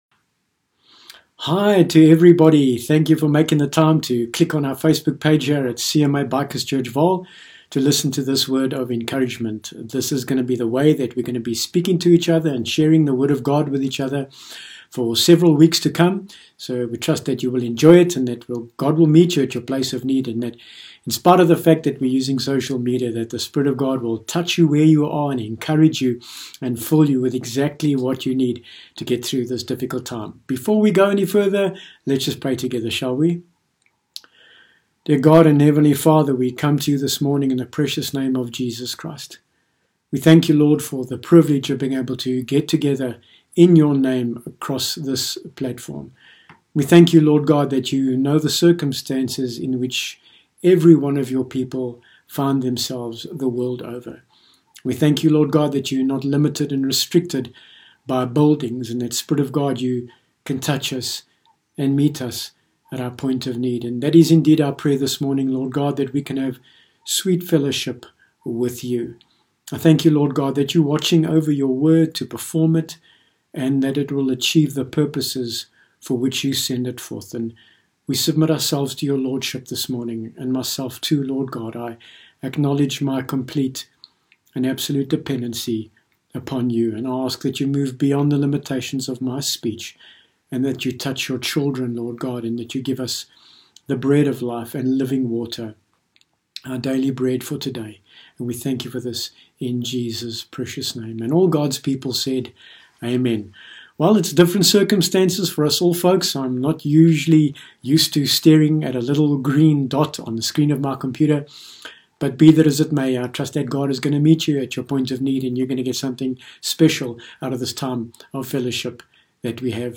Bible Text: Romans 8 : 37 – 39 | Preacher